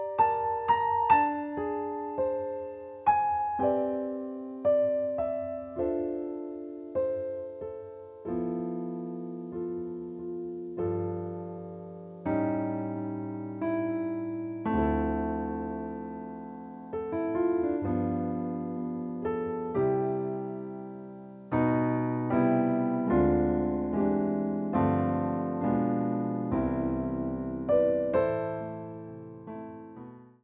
Piano Stylings